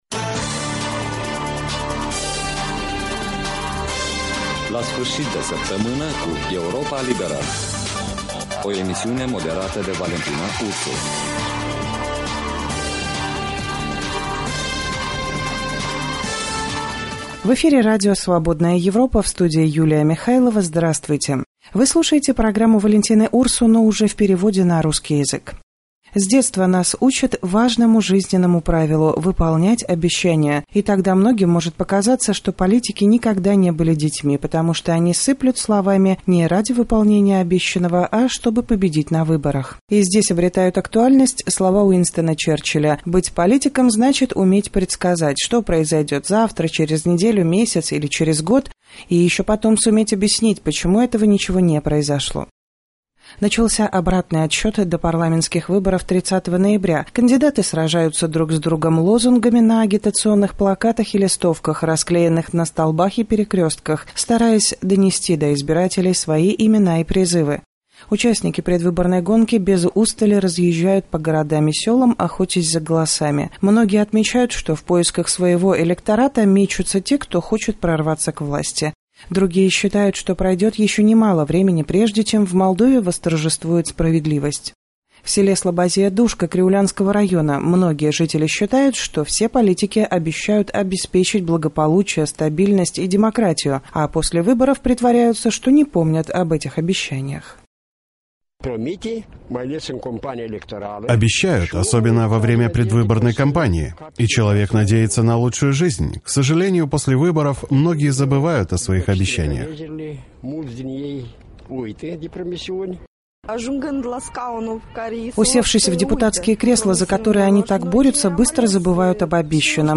В завершении недели о своих настроениях рассказывают жители села Слобозия-Душка
беседа